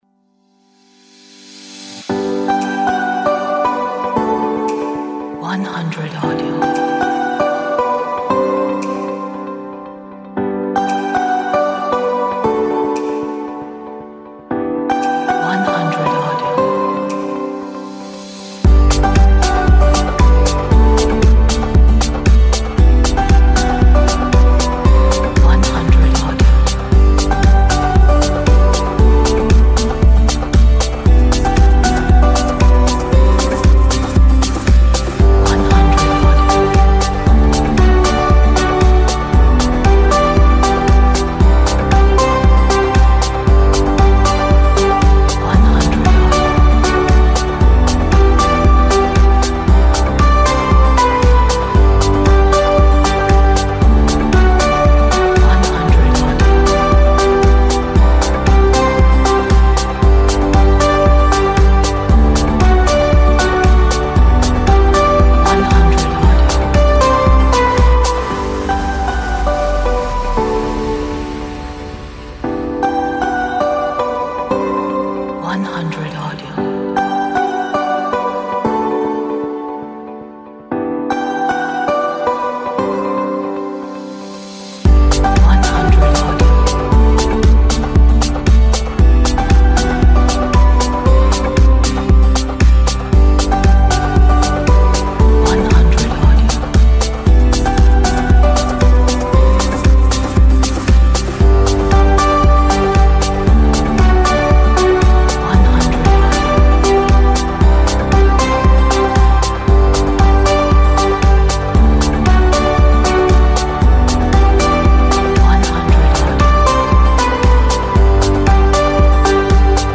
一首精彩绝伦的流行歌曲 一首很好的流行乐鼓舞人心的歌曲